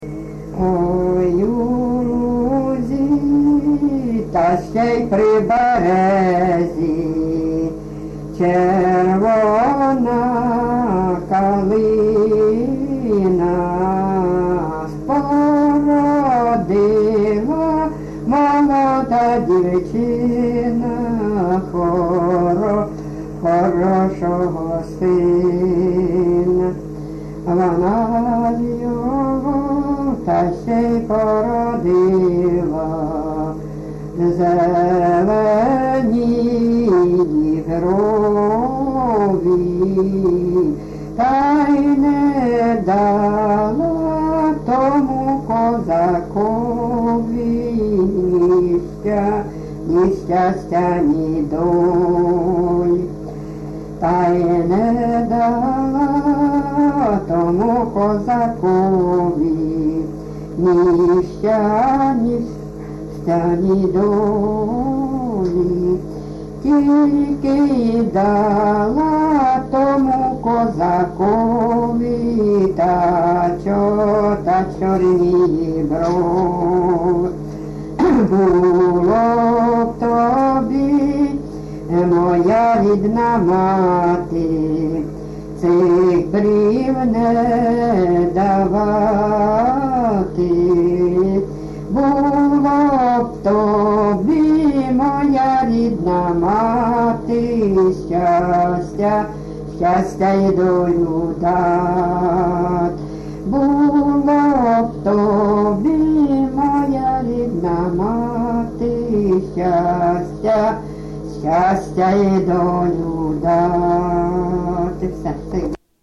ЖанрПісні з особистого та родинного життя
Місце записус. Остапівка, Миргородський район, Полтавська обл., Україна, Полтавщина